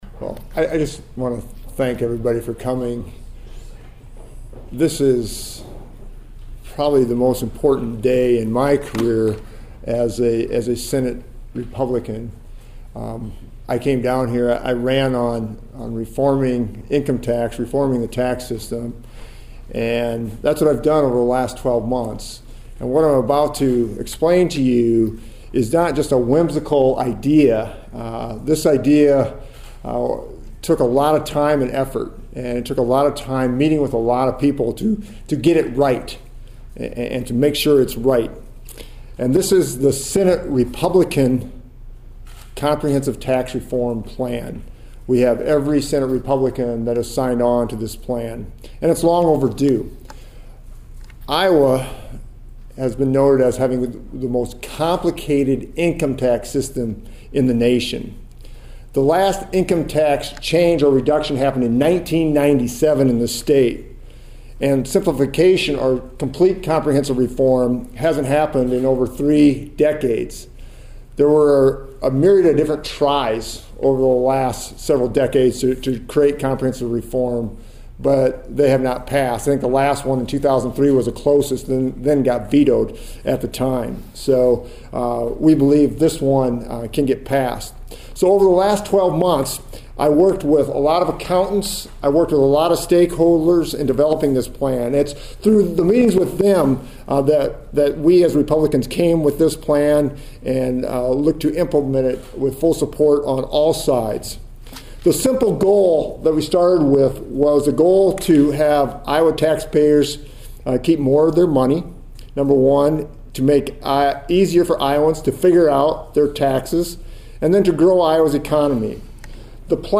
Senator Randy Feenstra, a Republican from Hull, revealed details of the plan at a statehouse news conference.